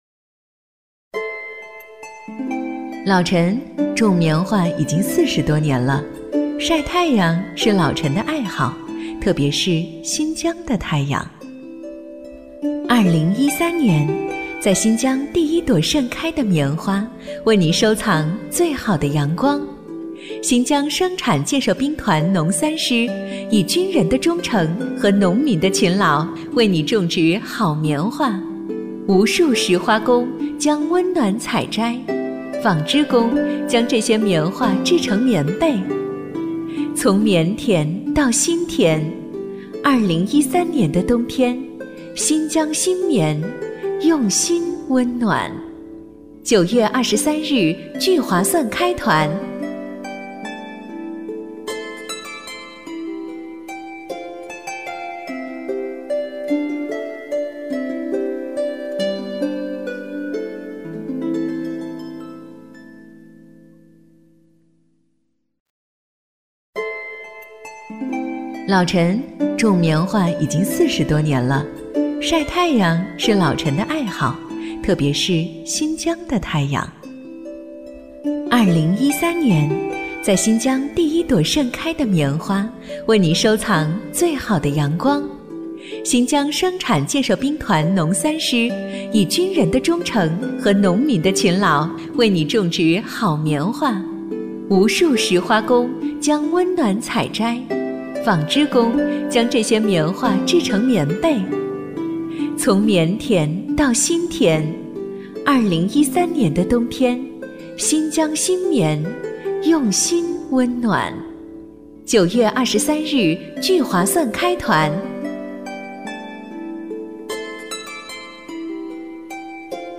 国语 女声 童声-女童-家居 讲故事 亲切甜美|素人